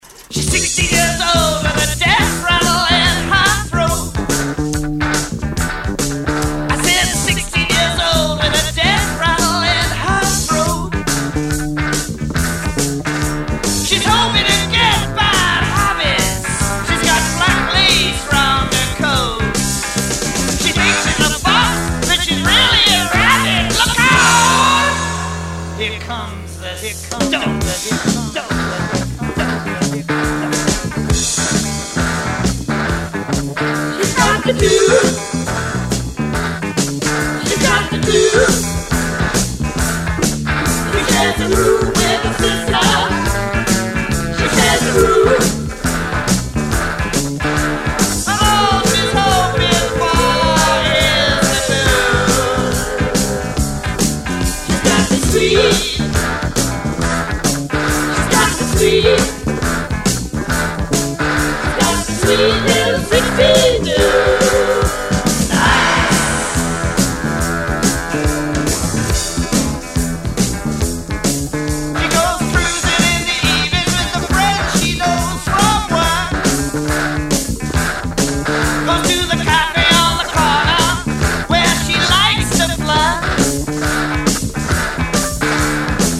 re-mastered from the original tapes